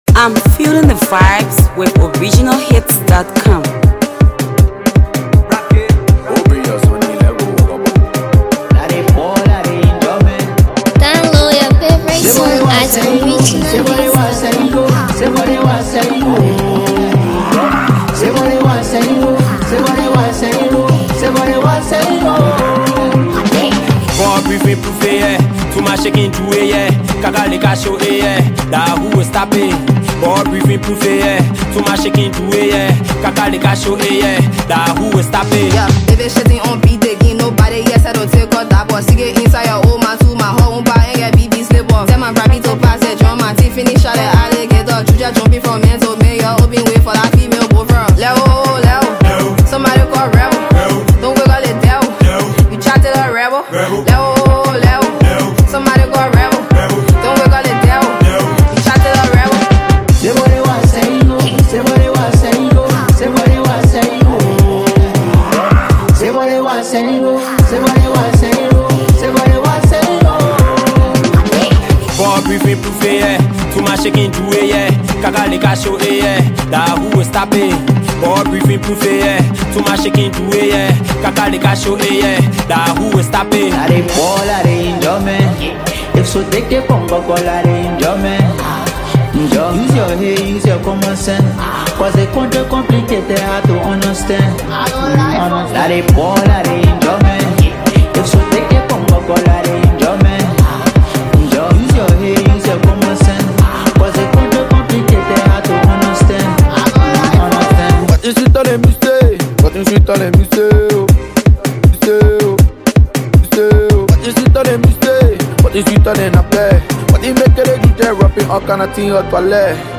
impressive studio effort